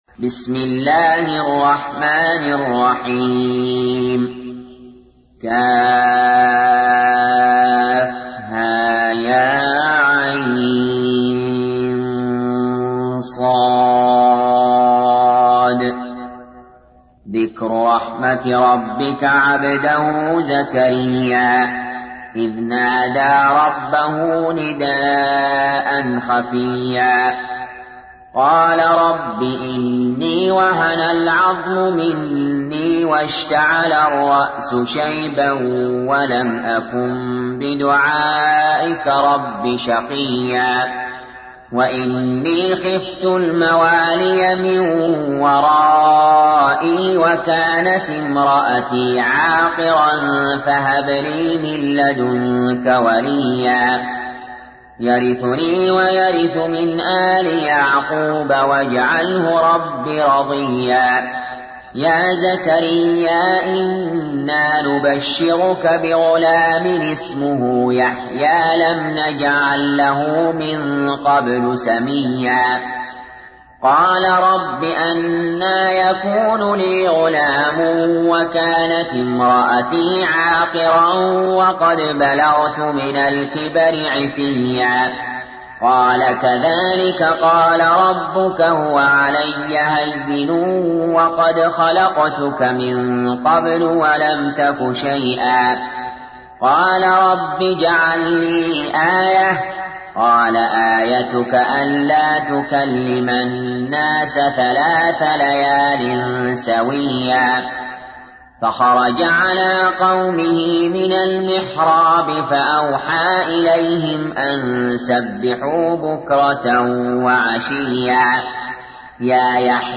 تحميل : 19. سورة مريم / القارئ علي جابر / القرآن الكريم / موقع يا حسين